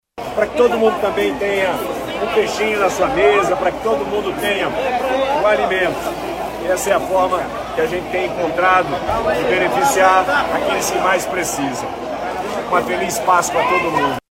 As duas ações têm o objetivo de garantir a segurança alimentar para famílias em vulnerabilidade no período da Páscoa, como explica o governador Wilson Lima.